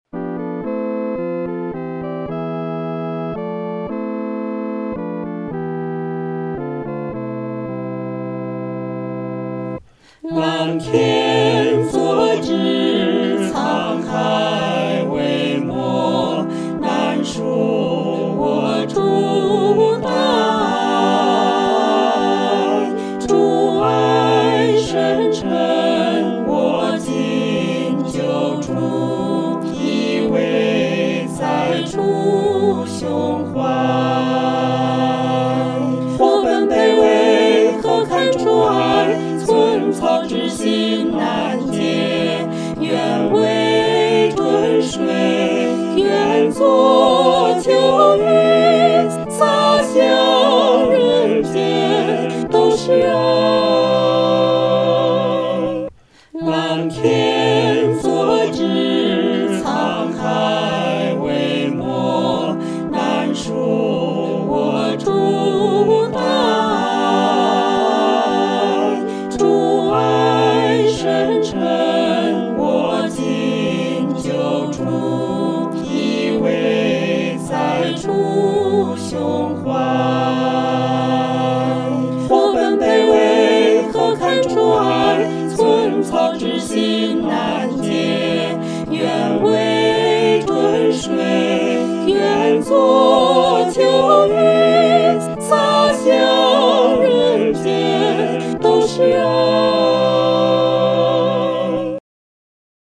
合唱
四声
这是一首抒情的圣诗，我们当用感恩的心唱颂。速度不宜快。